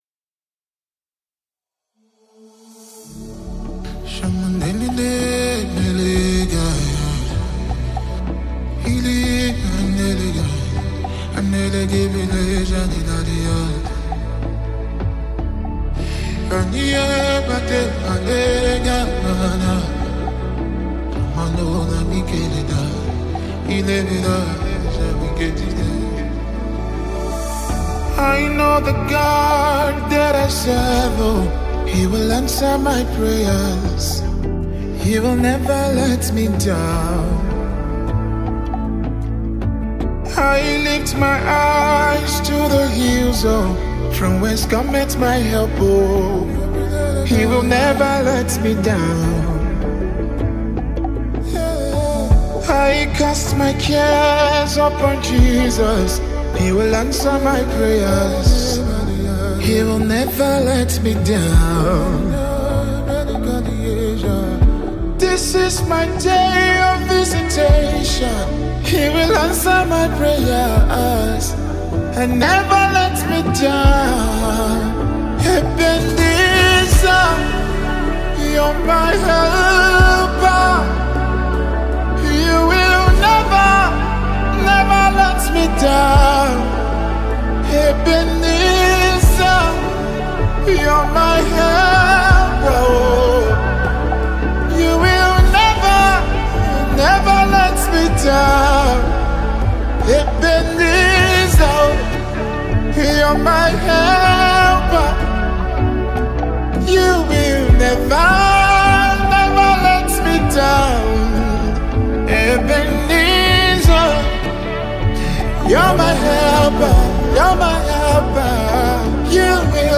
Nigerian gospel sensation
a heartfelt song of gratitude and worship.
delivers each lyric with passion and sincerity